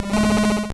Cri d'Écrémeuh dans Pokémon Or et Argent.